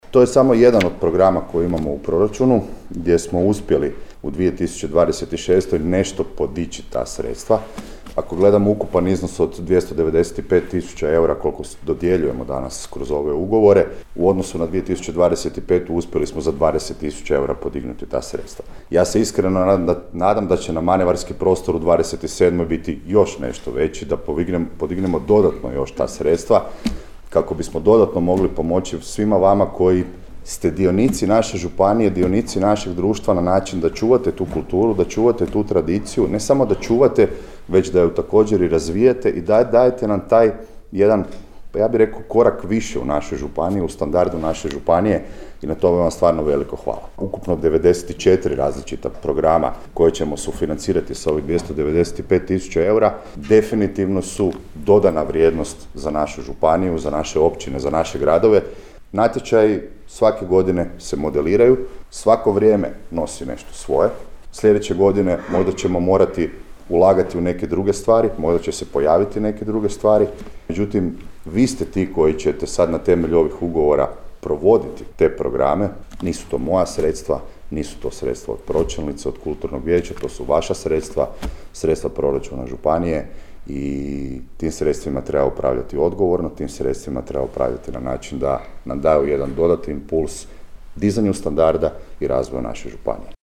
U prostorima Hrvatske gospodarske komore Županijske komore Koprivnica održano je potpisivanje ugovora o dodjeli financijskih sredstava udrugama i ustanovama iz područja kulture te vjerskim zajednicama.
-zaključio je župan.